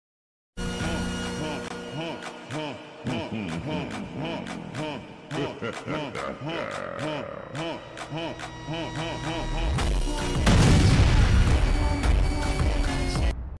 Bomb Explosion//Murder drones // HUMAN sound effects free download